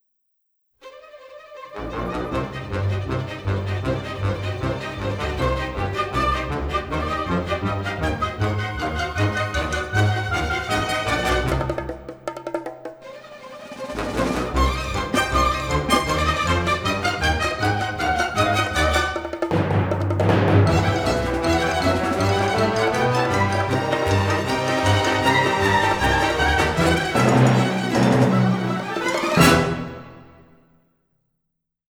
dynamic and classic fully orchestral adventure score